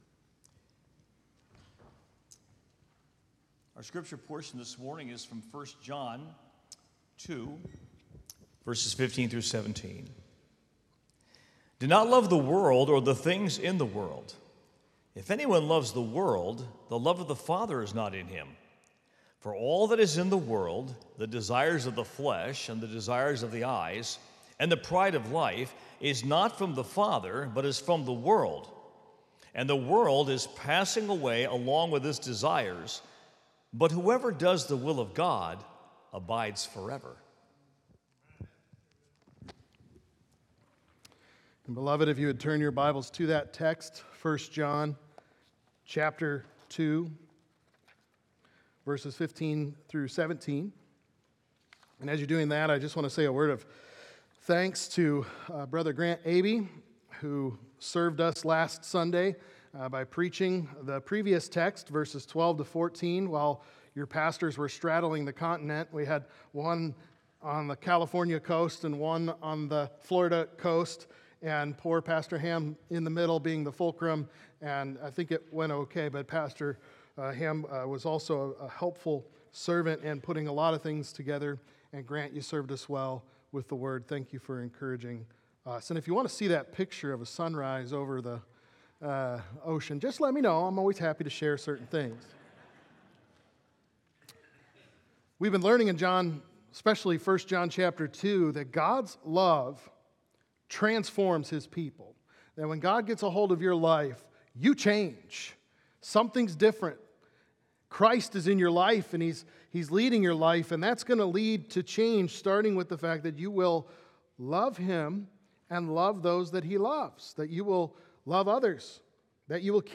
Love That Lasts | Baptist Church in Jamestown, Ohio, dedicated to a spirit of unity, prayer, and spiritual growth